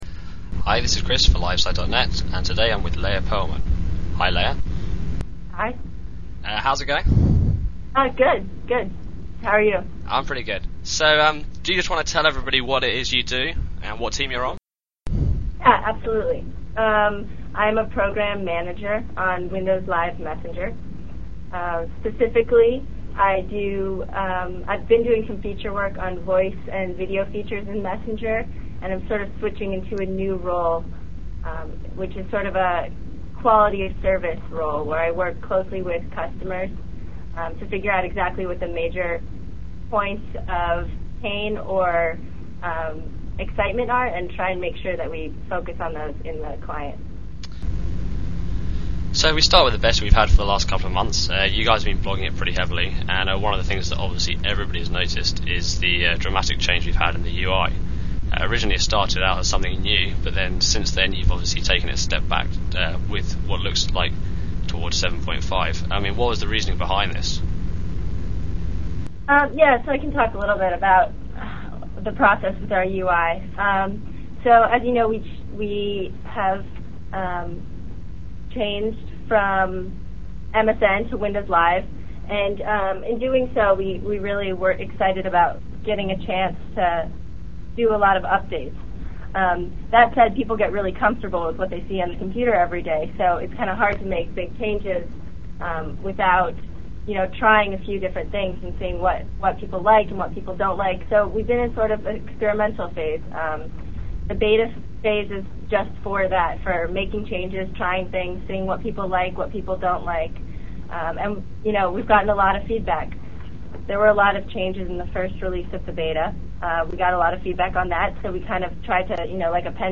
The 20 minute interview focuses on several important issues surrounding the upcoming Messenger 8.0 release, including: User Interface changes, Windows Live integration, Status Icons, Tabbed Chatting and lots of other little bits and pieces. You can download the complete interview below in MP3 format (9.2 MB).